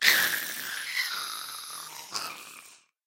Minecraft Version Minecraft Version snapshot Latest Release | Latest Snapshot snapshot / assets / minecraft / sounds / mob / ghast / death.ogg Compare With Compare With Latest Release | Latest Snapshot